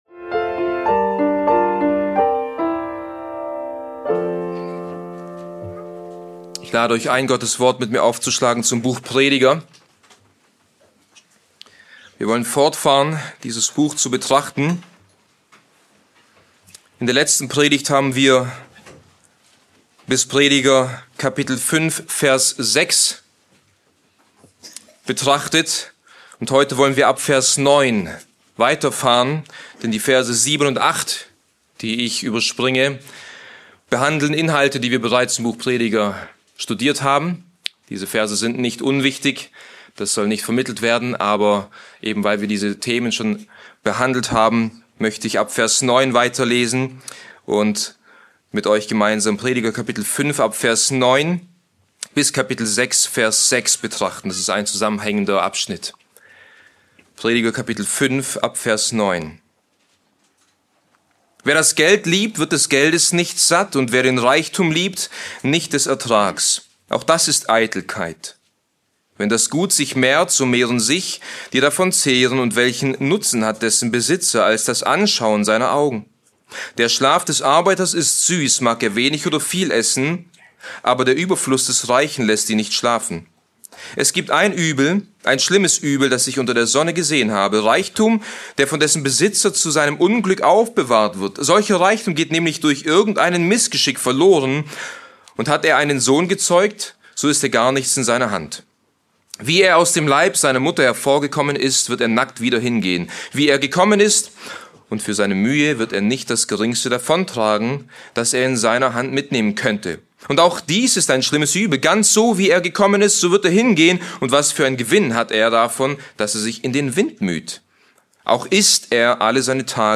Bibeltreue Predigten der Evangelisch-Baptistischen Christusgemeinde Podcast